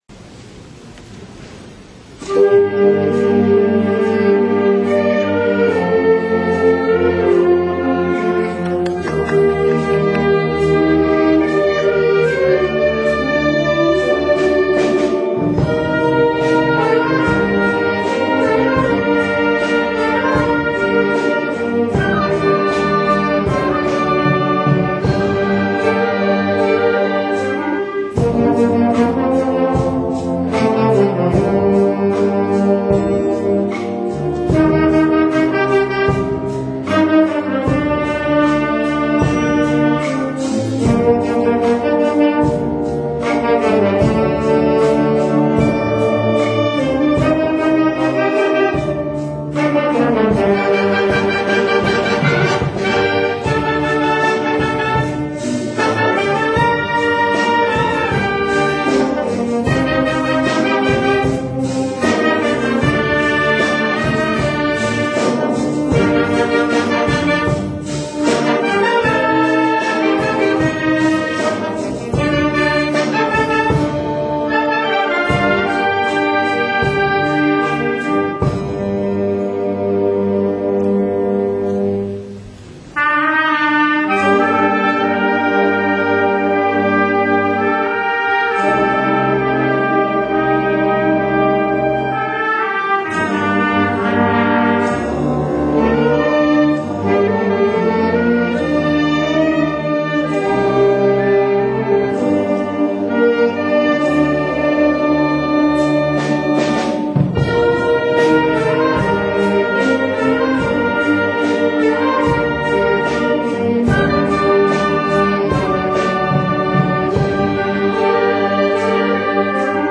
９月１６日（土）『嵐山東敬老のつどい』　に吹奏楽部が出演させていただきました
９月１８日（月祝）の敬老の日を前に、「嵐山東学区の敬老の集い」が開催されました。
舟唄」「レット・イット・ビー」「見上げてごらん夜の星を」「「時代劇スペシャル」「学園天国」、アンコールに「明日があるさ」の計８曲を演奏させていただき、大きな手拍子もしていただき感激でした。